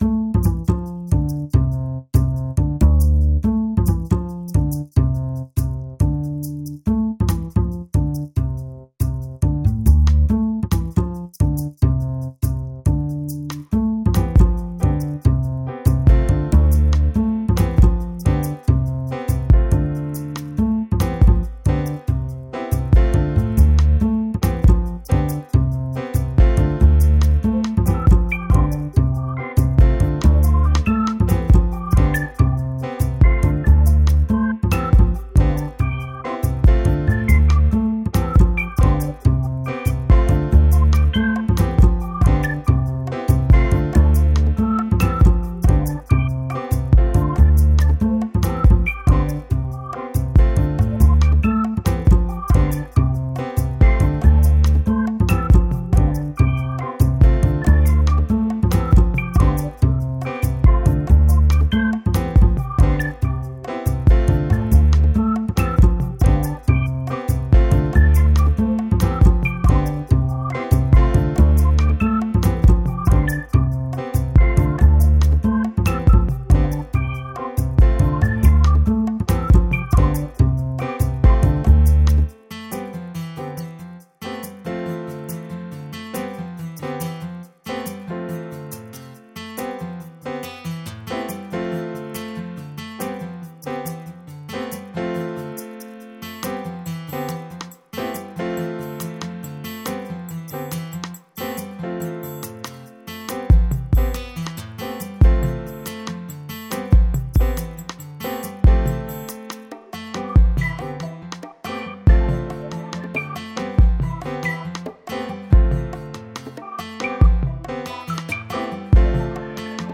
Nu jazz funky thing